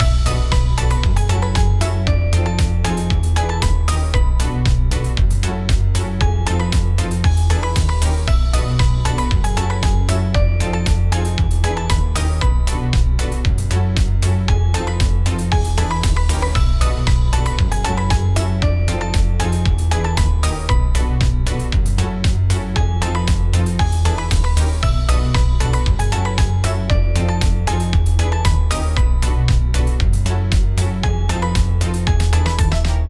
怖い・不穏
【ループ＆バリエーション版あり】ちょっぴりホラー？で不思議な雰囲気のBGMです◎
▼激しめ部分のみループ版